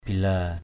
/bi-la:/